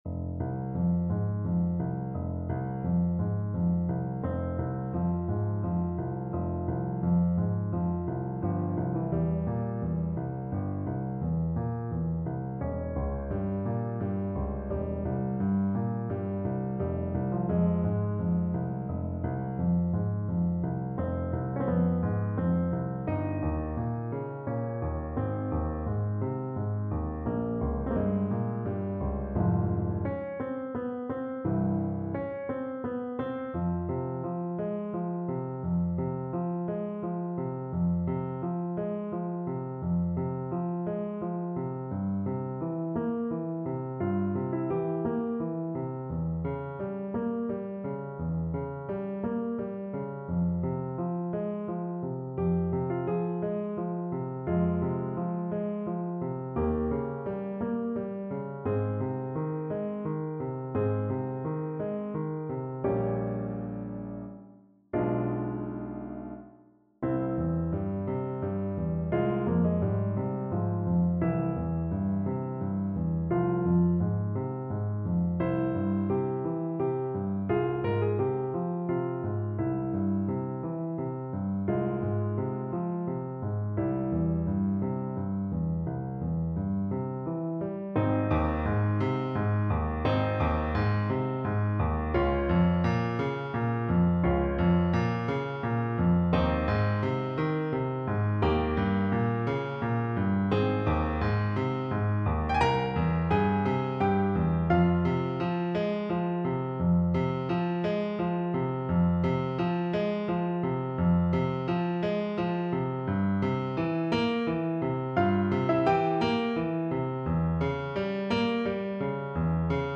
Play (or use space bar on your keyboard) Pause Music Playalong - Piano Accompaniment Playalong Band Accompaniment not yet available transpose reset tempo print settings full screen
Voice
Larghetto = c. 86
F minor (Sounding Pitch) (View more F minor Music for Voice )
6/8 (View more 6/8 Music)
Classical (View more Classical Voice Music)